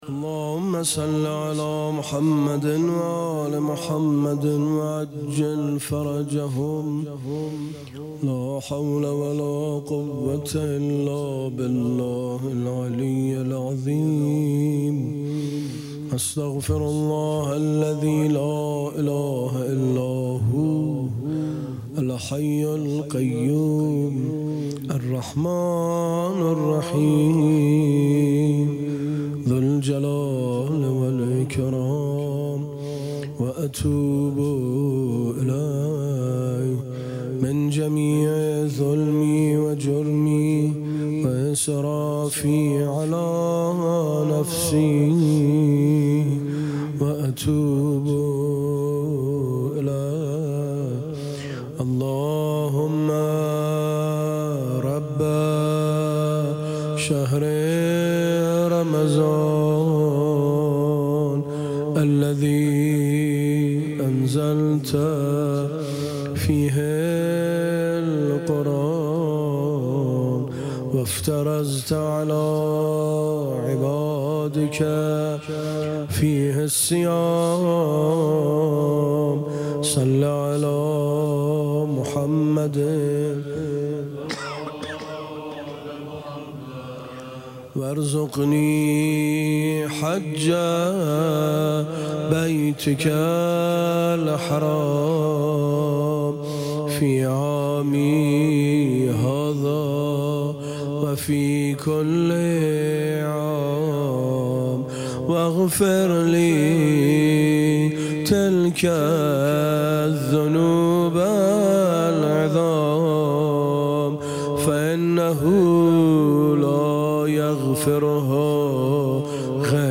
شب هفدهم ماه رمضان
مناجات خوانی